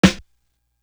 Tick Tock Snare.wav